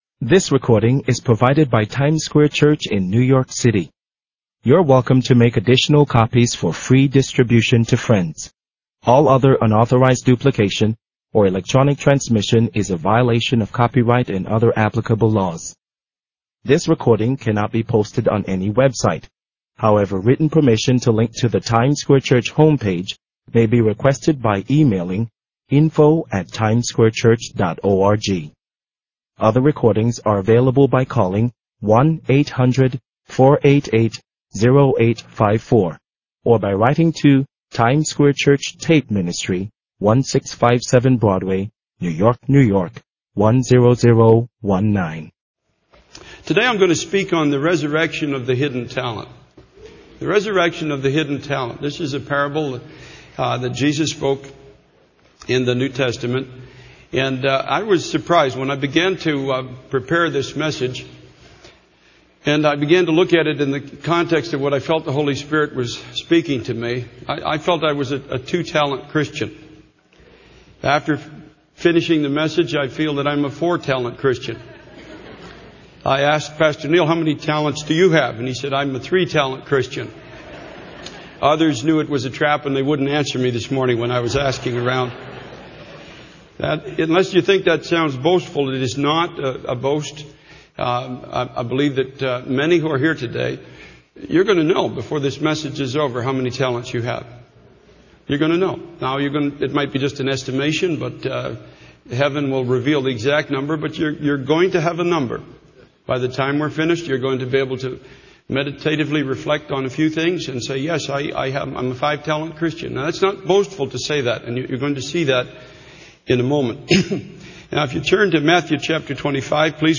In this sermon on Matthew chapter 25, the preacher discusses the parable of the talents.